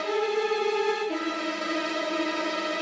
GS_Viols_85-AE.wav